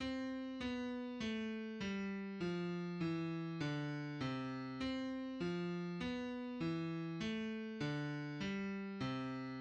Diatonic scale and the circle of fifths derived from it – major